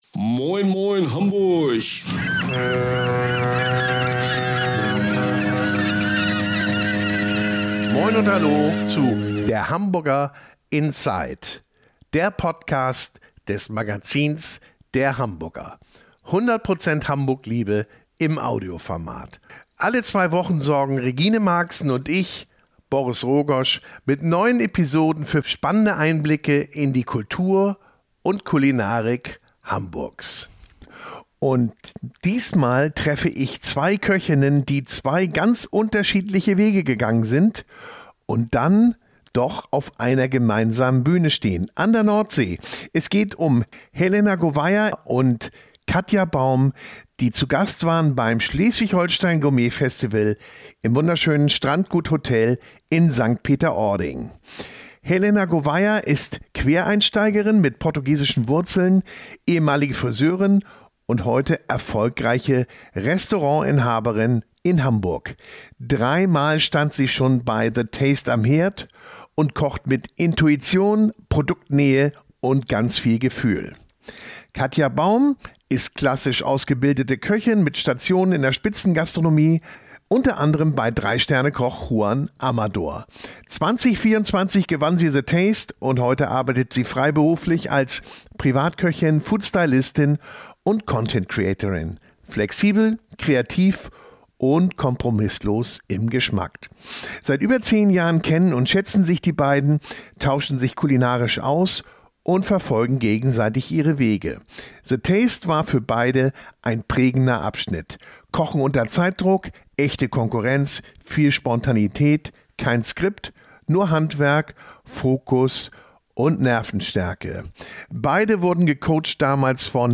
Im Gespräch geht es um Spontanität, Zeitdruck und Teamarbeit in fremden Küchen und um die Frage, wie wichtig Vertrauen im entscheidenden Augenblick ist. Zwei Hamburger Köchinnen, zwei unterschiedliche Wege und eine gemeinsame Bühne.